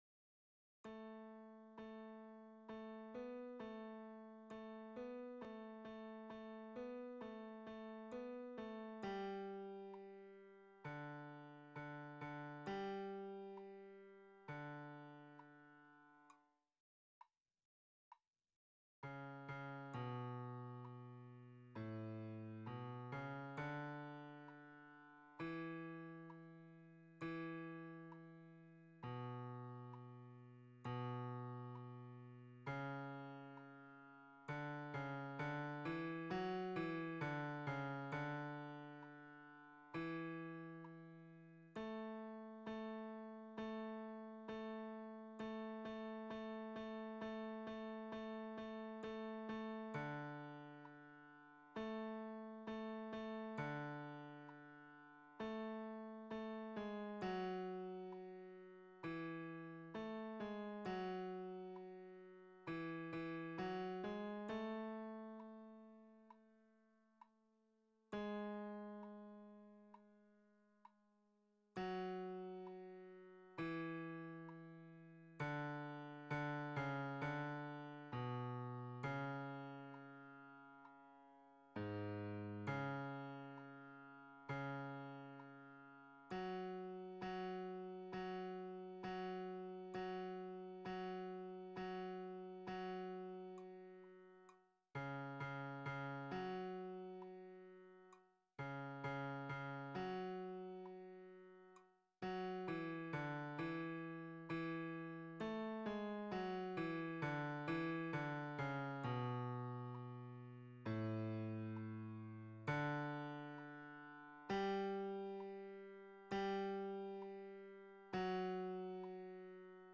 MP3 version piano
Bass